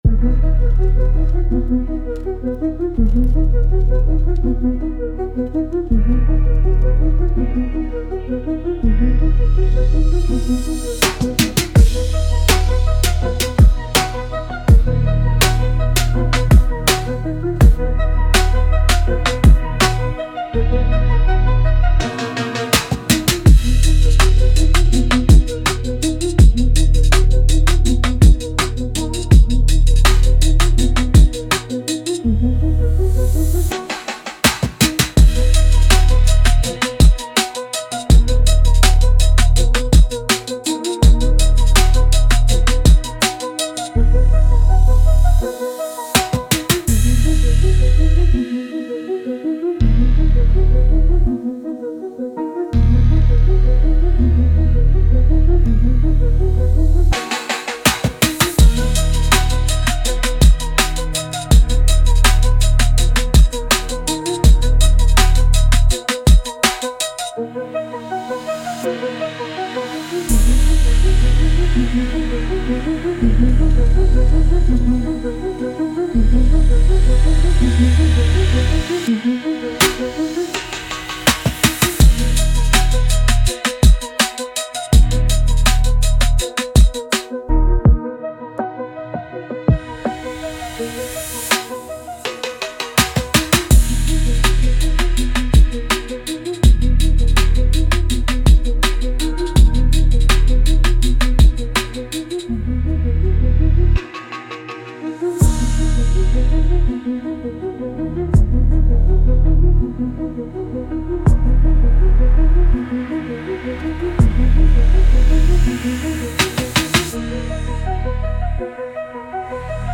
Instrumental - Real Liberty Media DOT xyz- 4. min